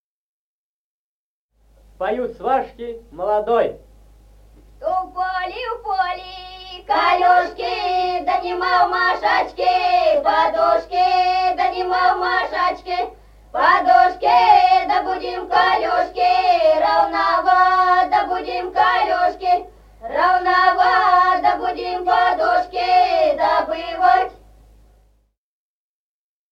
Народные песни Стародубского района «У поле, в поле», свадебная, свашки поют молодым.
1953 г., с. Мишковка.